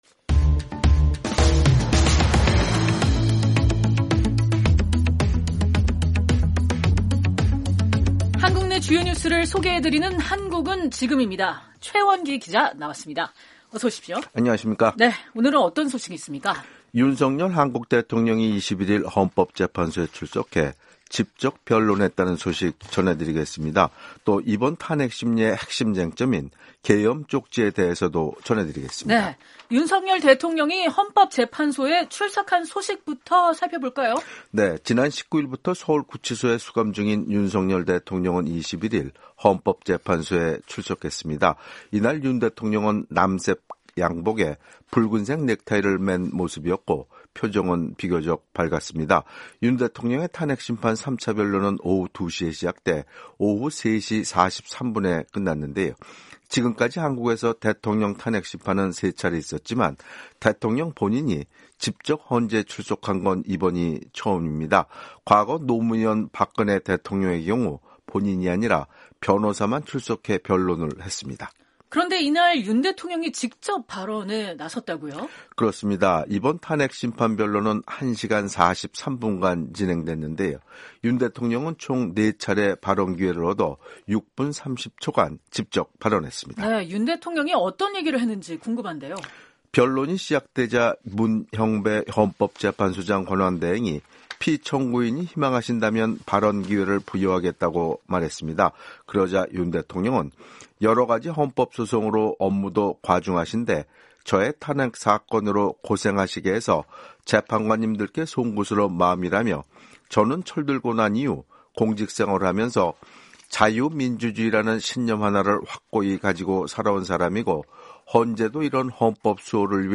한국 내 주요 뉴스를 소개해 드리는 ‘한국은 지금’입니다.